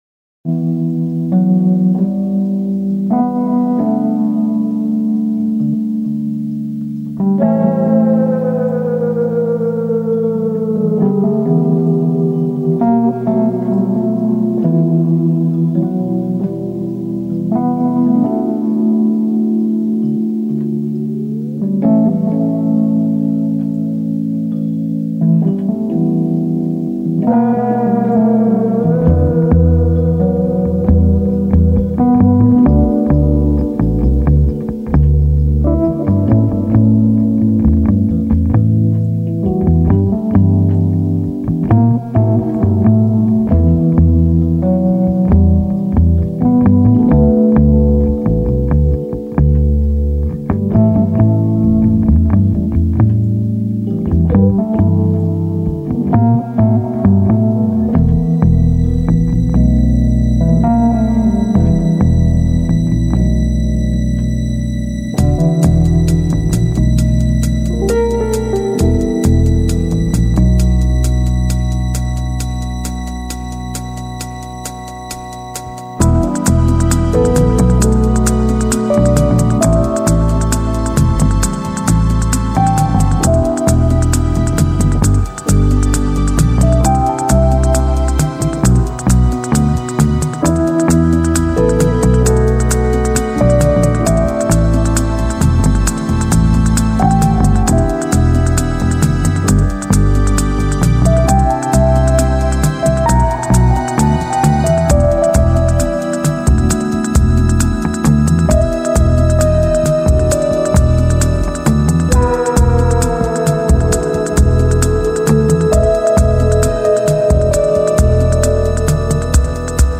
Because we all need a chill song in our lives!